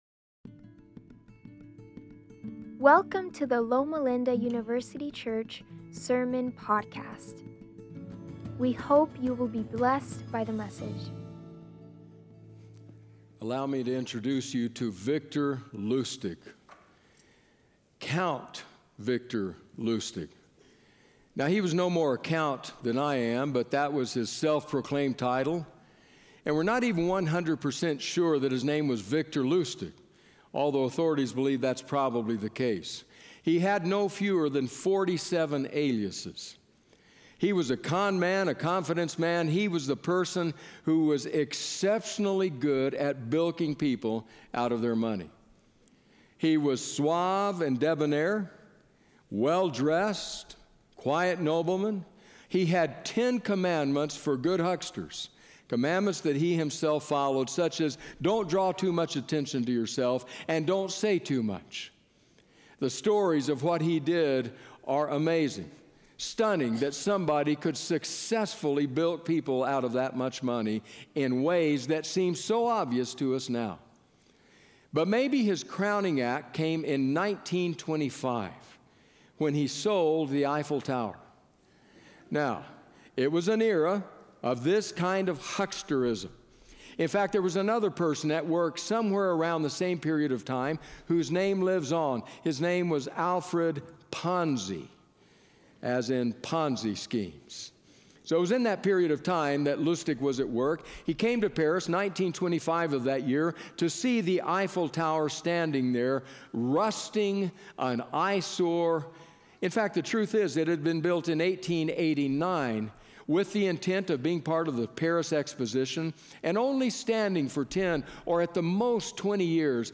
January 25, 2025: "In My Defense..." - LLUC Sermon Podcast